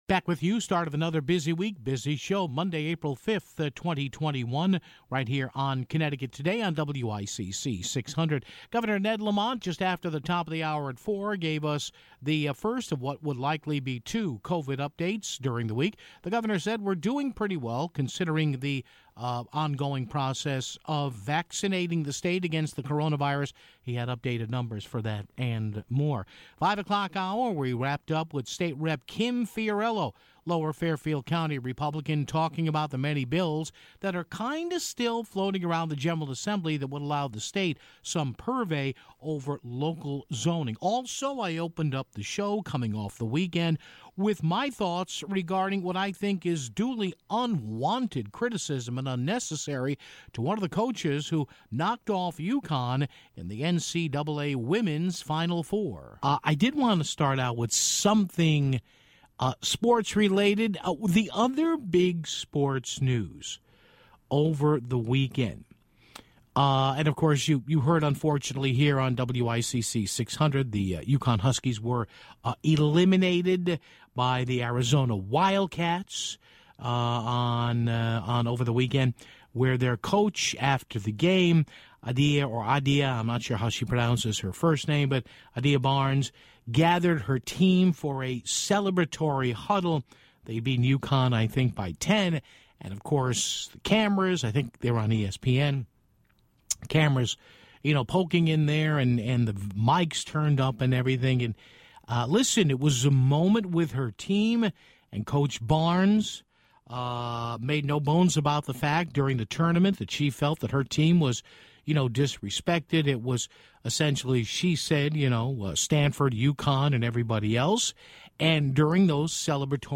Governor Ned Lamont gave the first of his twice-weekly news update on the coronavirus situation (7:07). Finally, Greenwich GOP State Representative Kim Fiorello called in to give an update and her thoughts on some controversial zoning bills at the State Capitol (25:47).